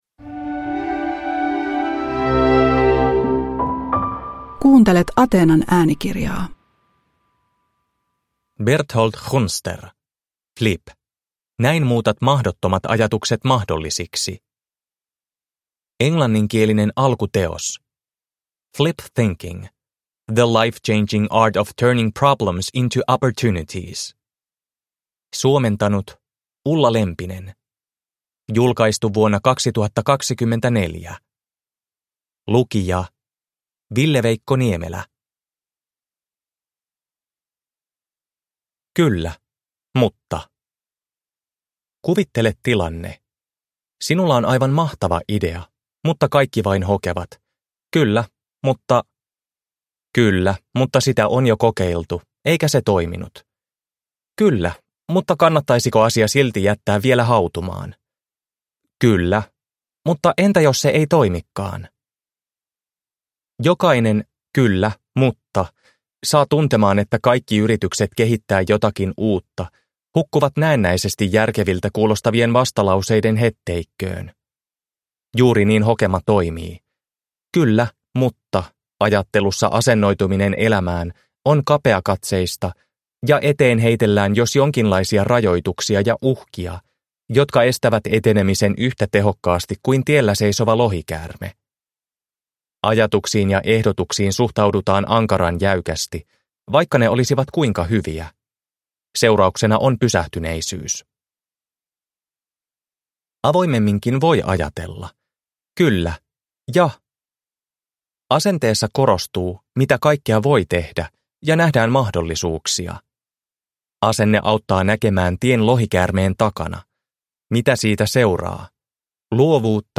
Flip (ljudbok) av Berthold Gunster